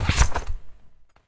holster_in_light.wav